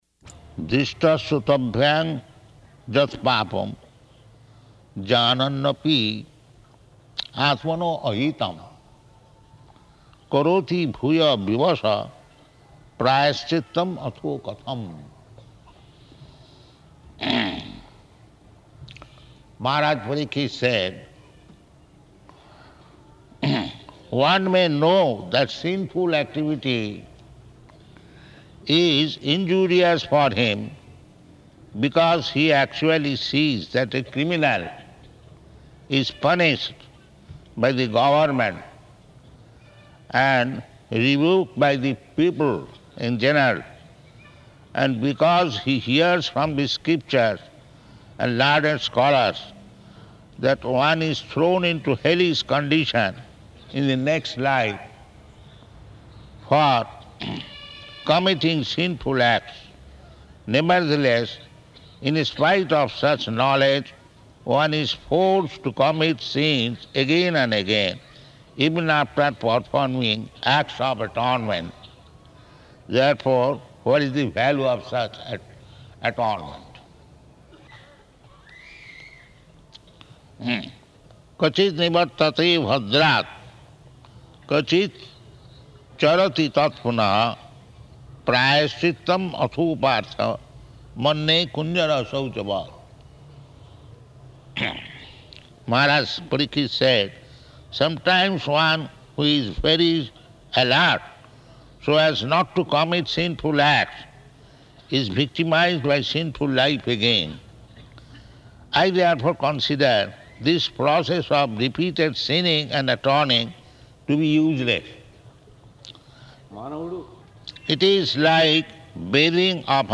Location: Nellore